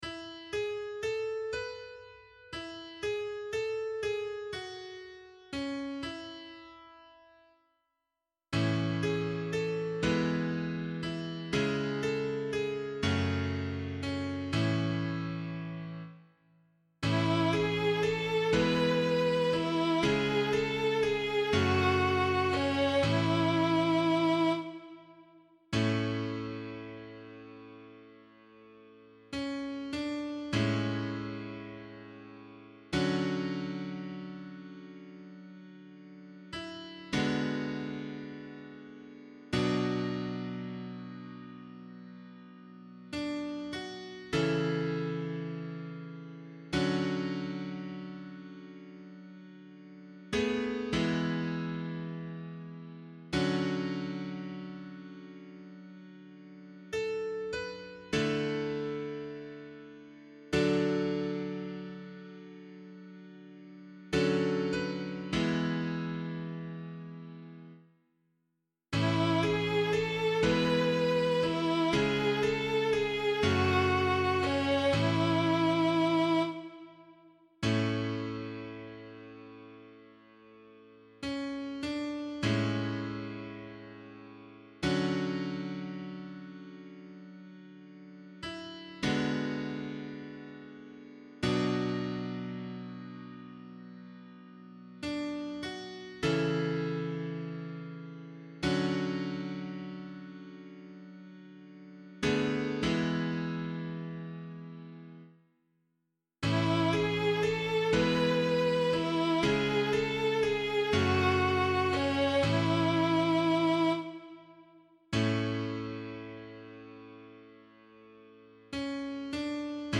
007 Holy Family Psalm C [APC - LiturgyShare + Meinrad 8] - piano.mp3